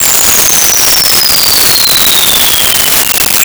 Bomb Fall 02
Bomb Fall 02.wav